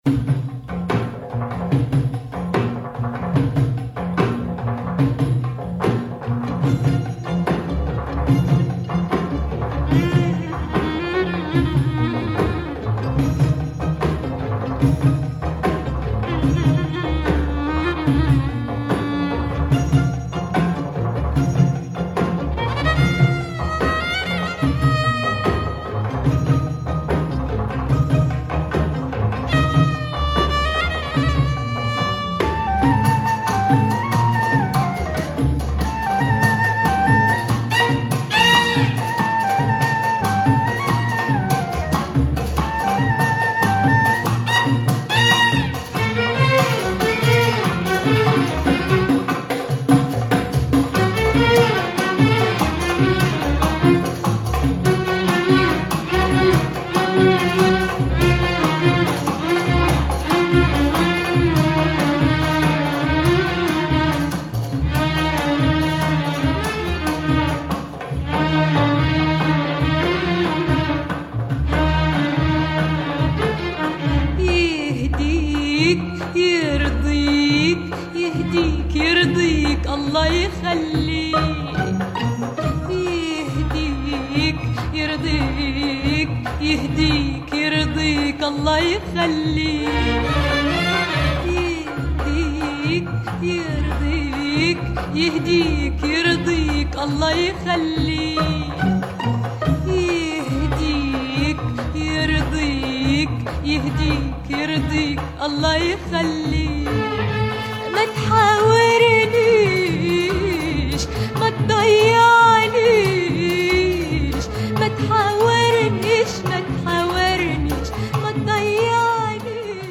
A rare Egyptian soundtrack.
with oriental organ